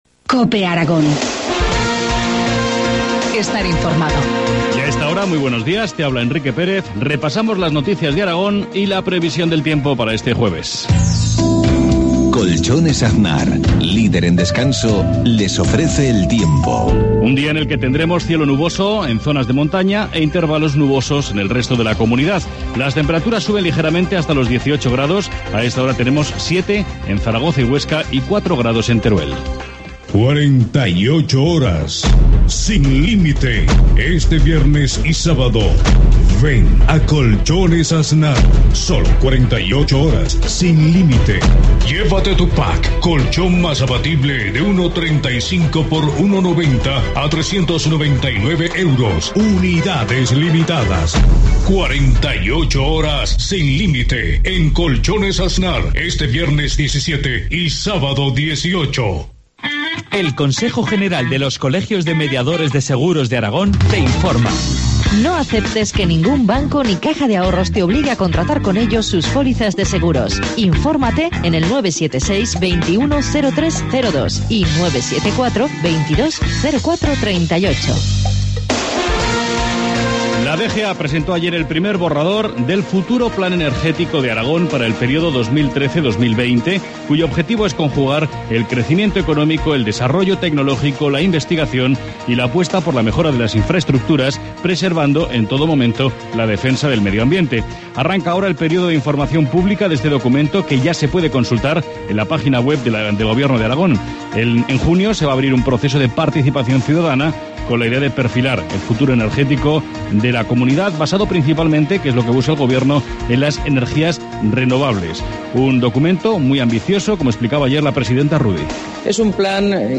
Informativo matinal, jueves 16 de mayo, 7.53 horas